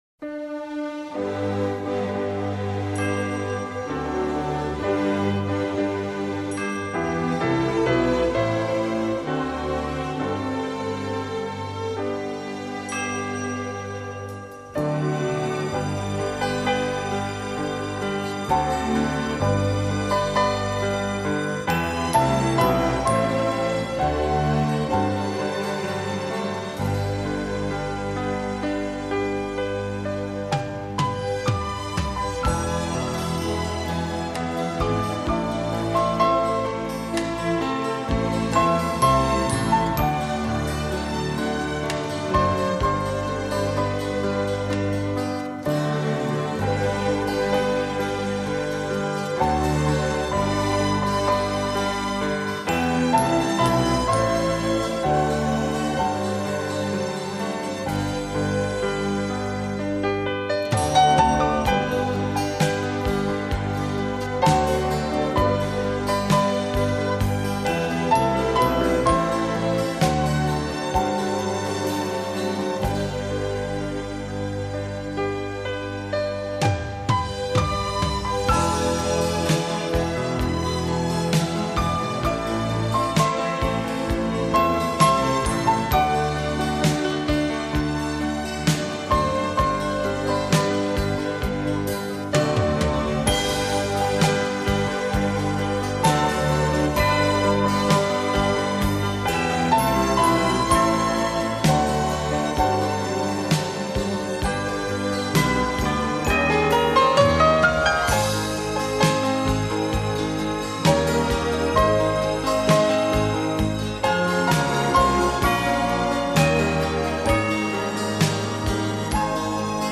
钢琴圣诞曲